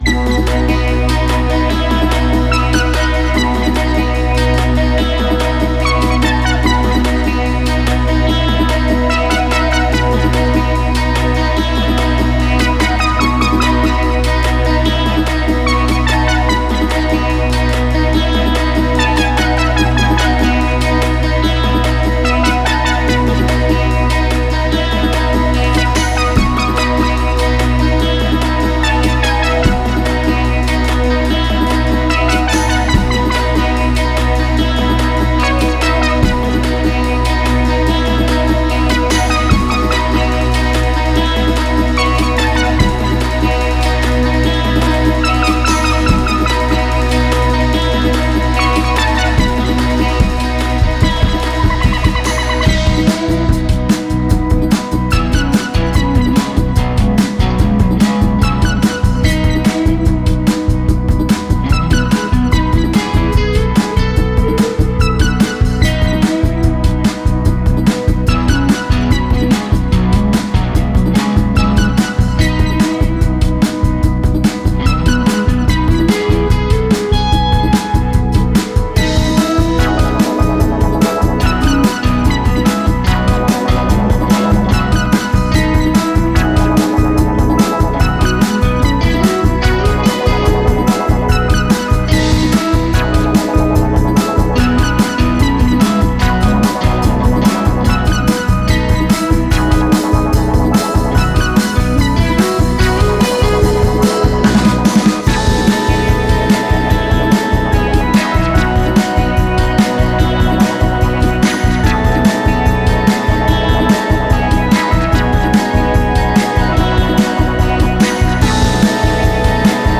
unknown session venue, somewhere in Ukraine